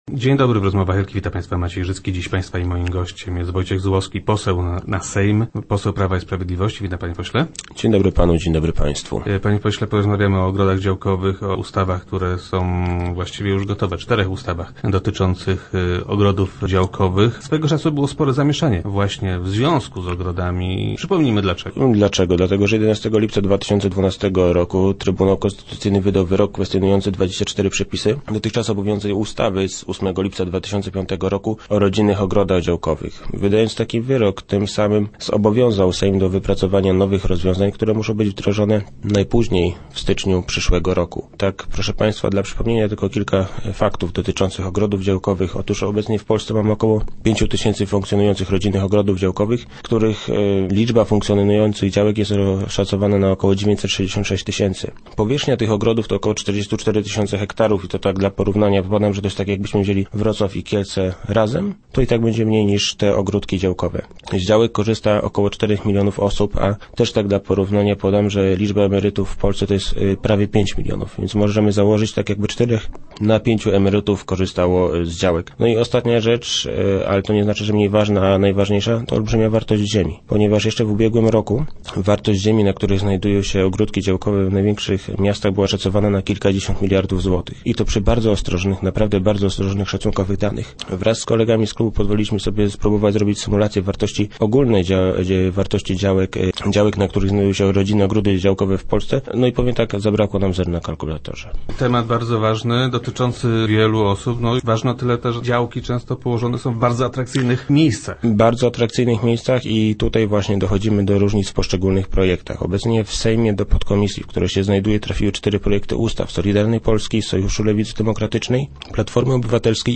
Gościem poniedziałkowych Rozmów Elki był poseł Wojciech Zubowski.